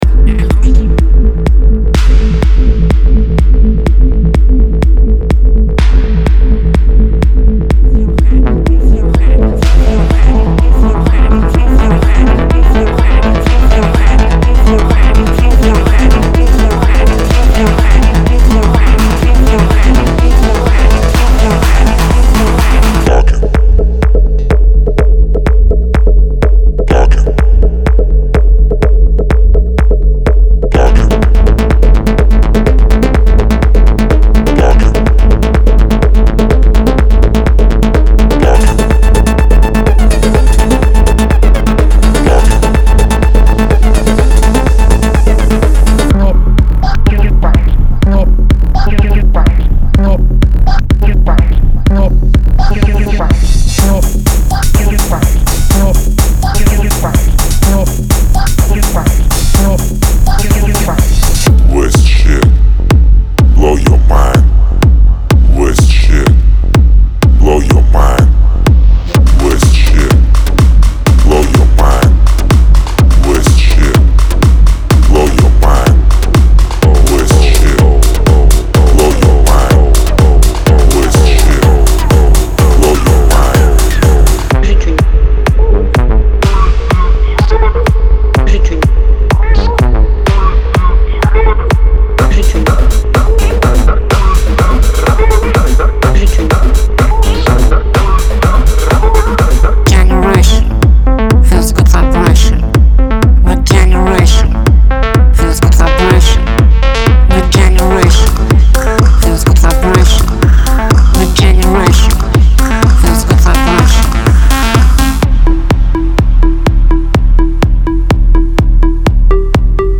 To conquer the Techno floor at Prime Time it certainly requires some serious dark matter, mercilessly destructive sonic arsenal and just those haunting vocal chops to make club addicts shiver.
– All Loops 125 bpm
24 bit WAV Stereo
Inside the pack, you’ll find all kinds of loops; bass, drum, kick, music, perc, top, vocal and FX.
Lots of crowd pleasers here; from pounding drums to resonant percussion and pitch-black synthesizer sounds to vocals, the compilation of samples in this tight sample is great from the get-go.